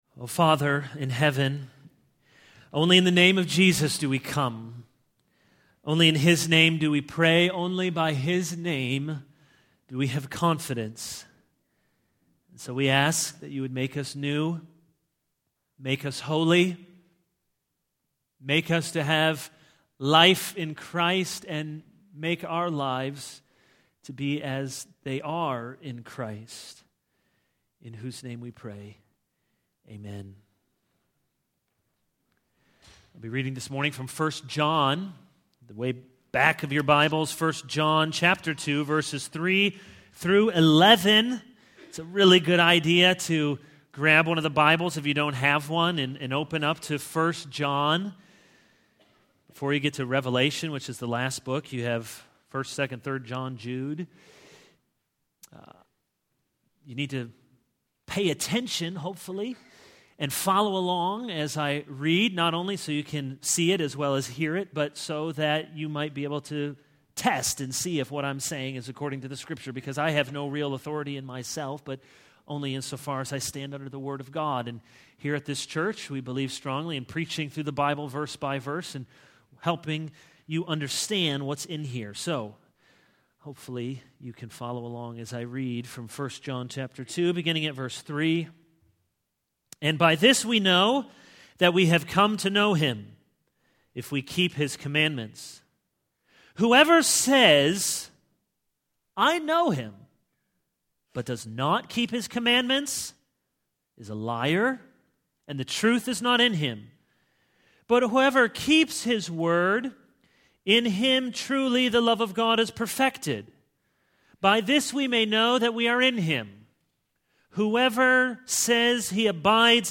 This is a sermon on 1 John 2:3-11.